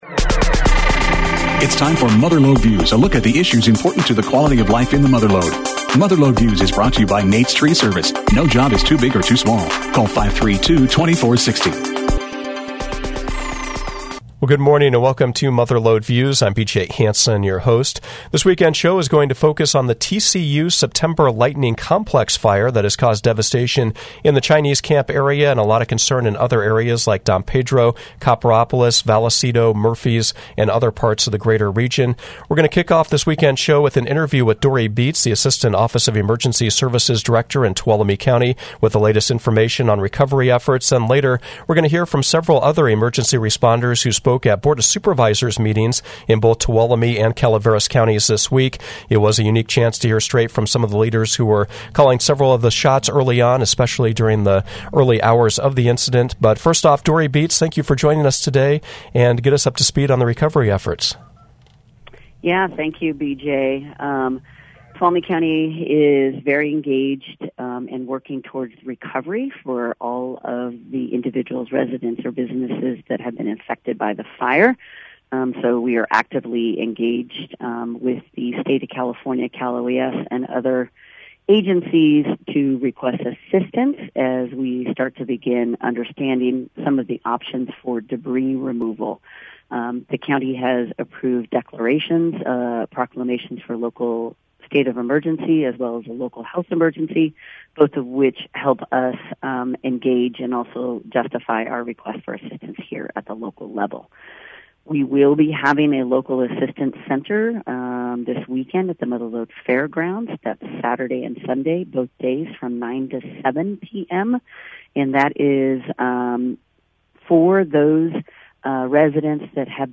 Mother Lode Views featured a recap of the efforts to fight the TCU Lightning Complex Fire that caused destruction in Chinese Camp, and lesser damage in Vallecito and Copperopolis. Speakers include Tuolumne County Sheriff David Vasquez, CAL Fire Tuolumne-Calaveras Unit Chief Nick Casci, Calaveras Office of Emergency Services Director Erik Holt, and others.